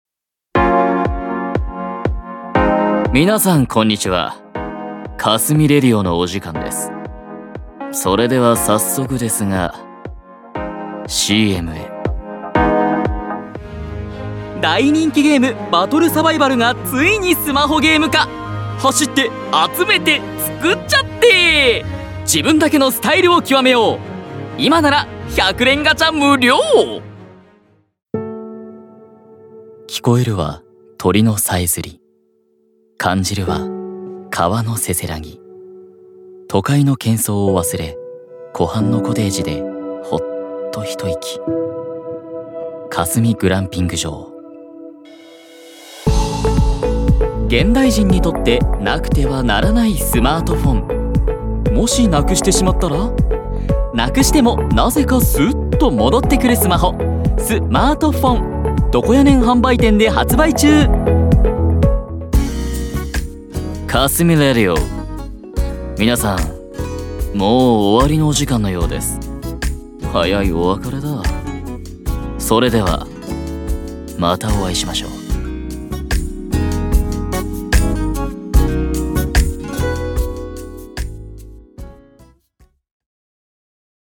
BGMあり